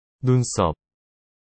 「眉毛」は韓国語で「눈썹（ヌンソップ）」と言います。